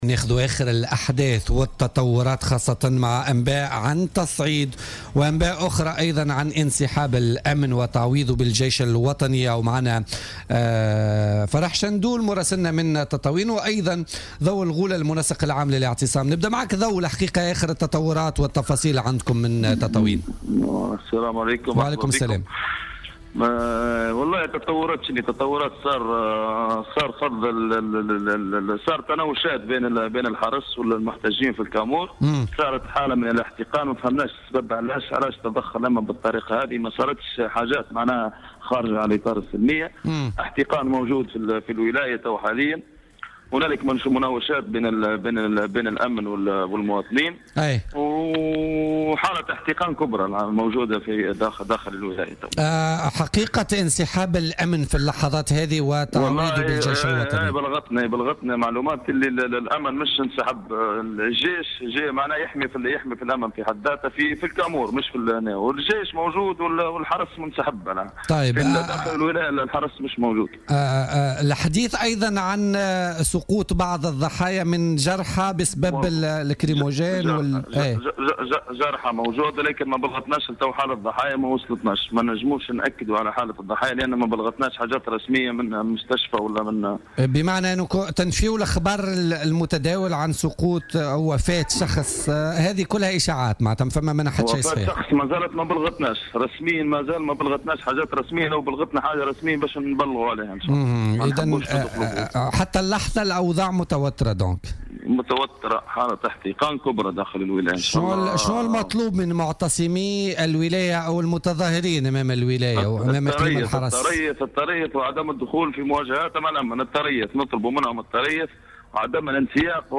وقال في اتصال هاتفي مع "الجوهرة اف أم" إن هذه المجموعات تحاول المساس من سلمية الاعتصام والانسياق خارجه.